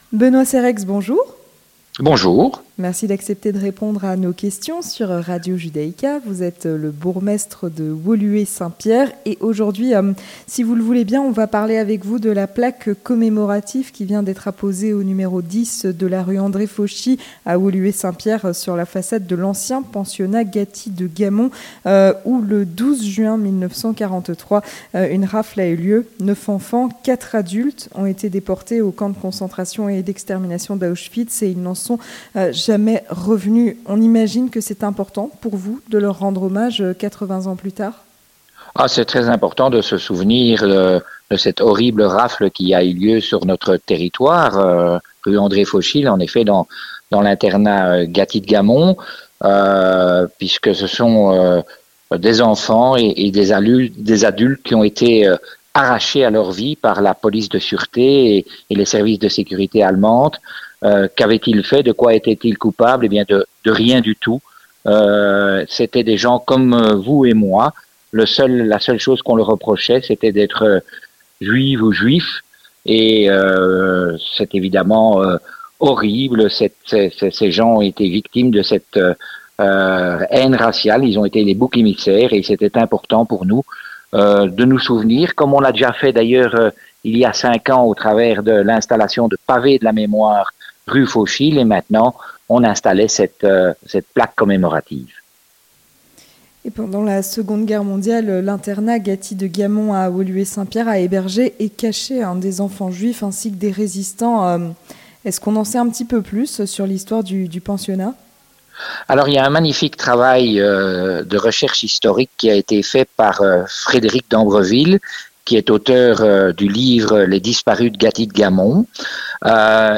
Avec Benoît Cerexhe, bourgmestre de Woluwe-Saint-Pierre